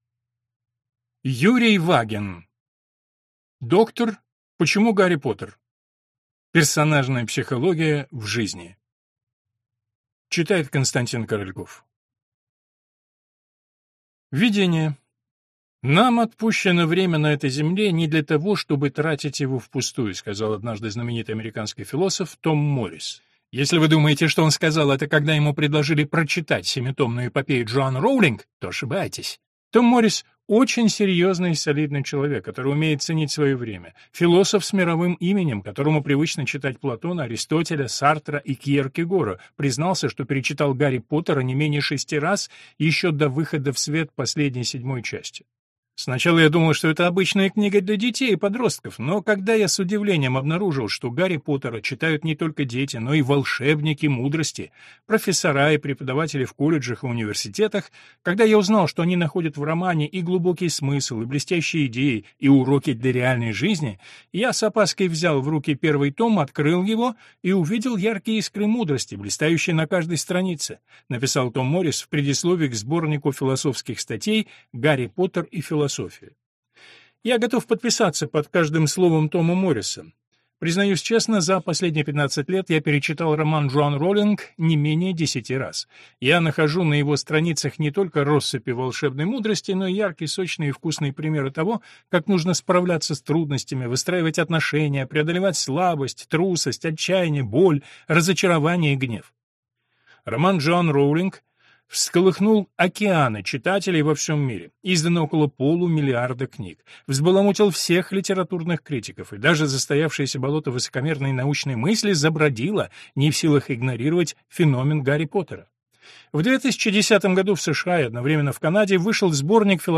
Аудиокнига Доктор, почему Гарри Поттер? Персонажная психология в жизни | Библиотека аудиокниг